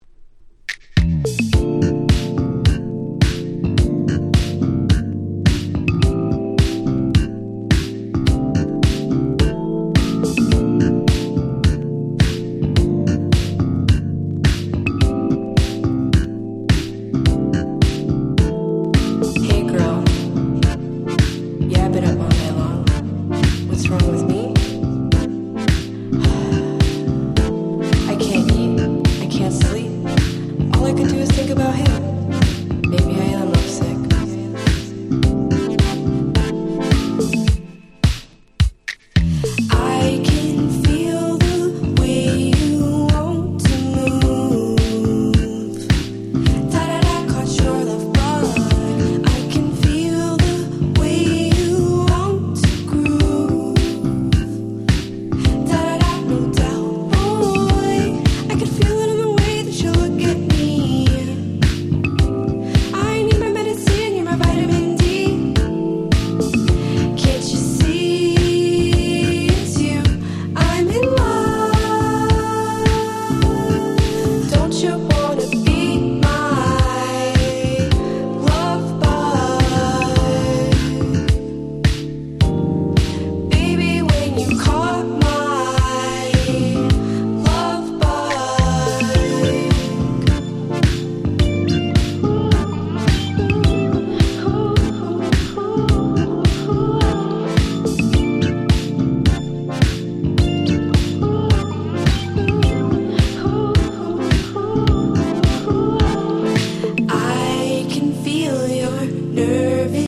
R&B調の気持ち良いModern Soulナンバー